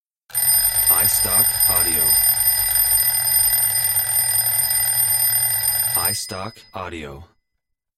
Old Alarm Clock Ringing
Digitally recorded of mechanical alarm clock ringing sound.